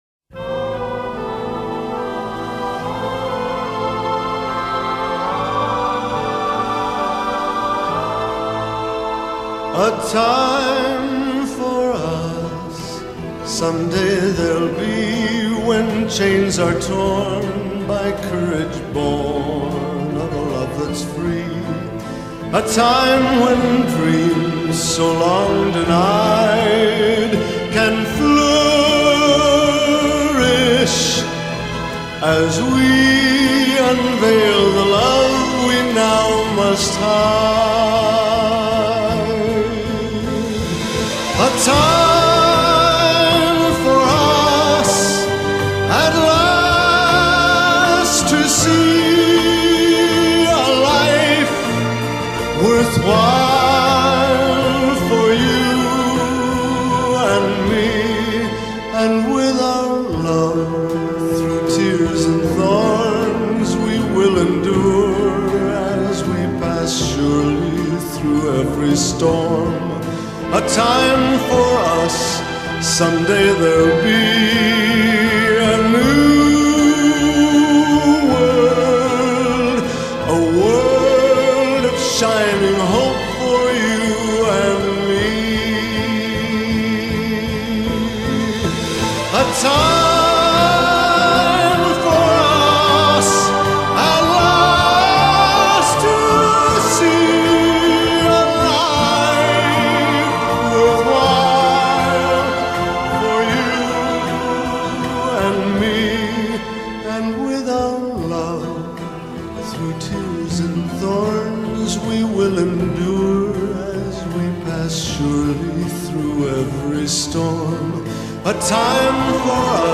English Song